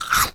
comedy_bite_creature_eating_05.wav